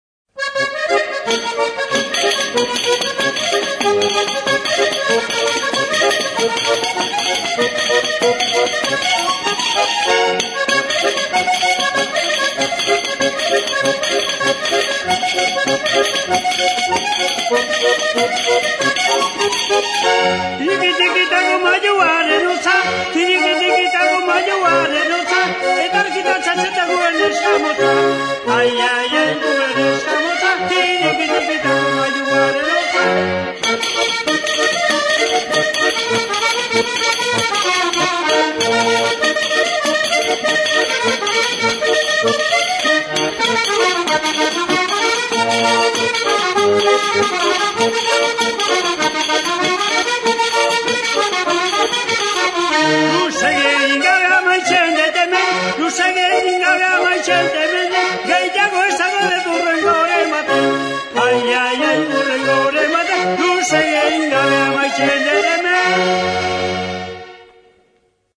Membranophones -> Beaten -> Tambourines
PANDEROA
Zurezko uztai zilindrikoa du. Alde batean tinkaturiko larruzko mintza dauka. Uztaian bi lerrotan jarririk, metalezko 11 txinda pare ditu.